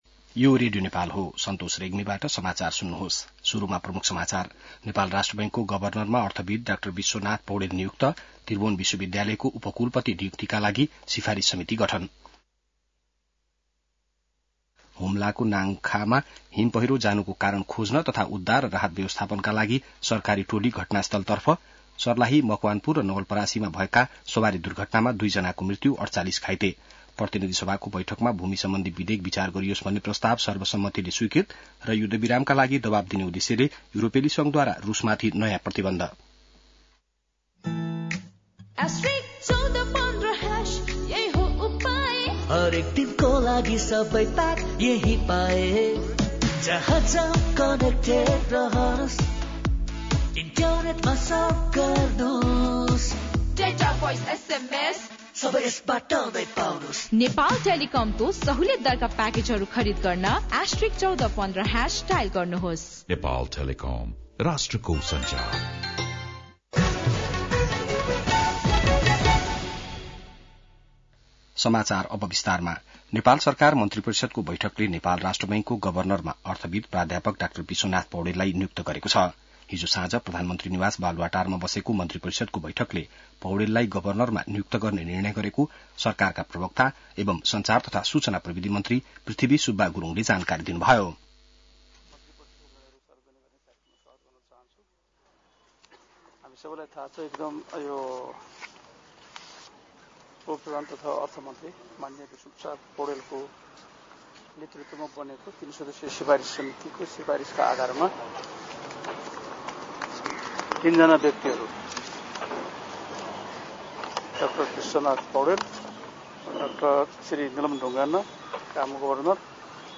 बिहान ७ बजेको नेपाली समाचार : ७ जेठ , २०८२